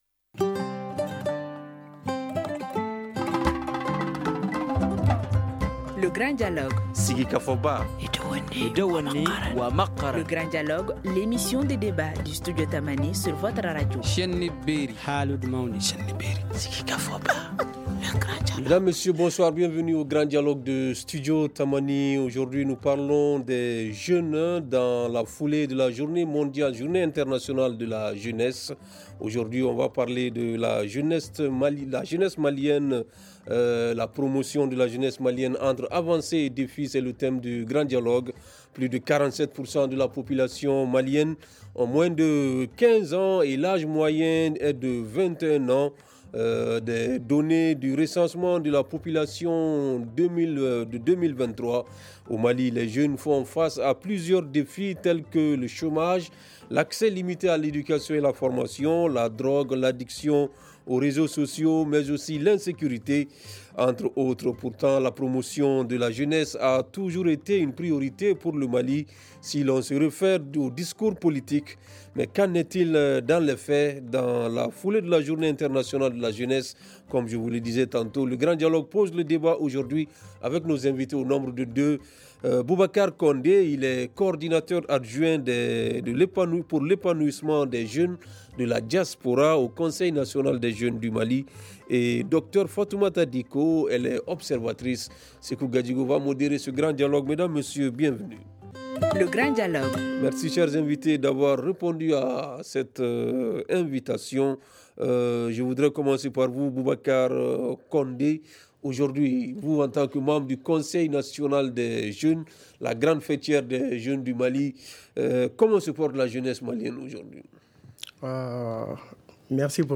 Mais qu’en est-il dans les faits ? Dans la foulée de la journée internationale de la jeunesse, le grand dialogue pose le débat avec nos invités.